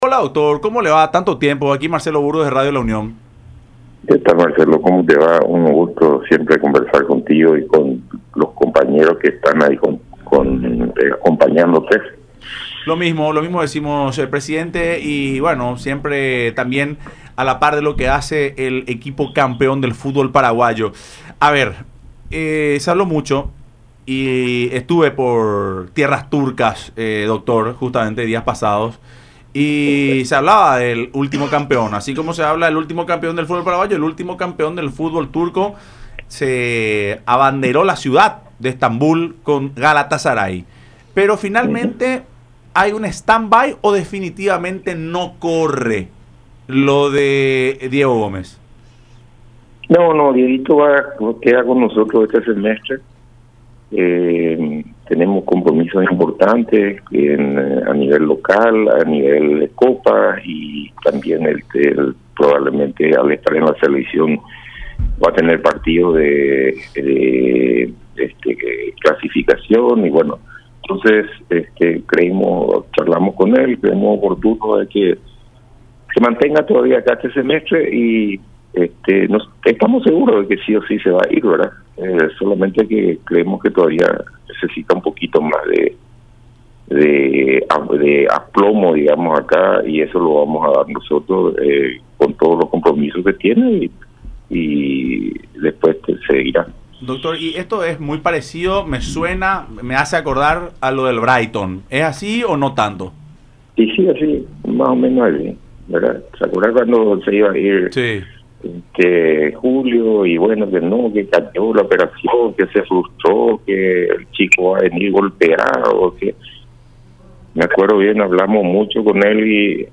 En contacto con Fútbol Club, por radio la Unión y Unión TV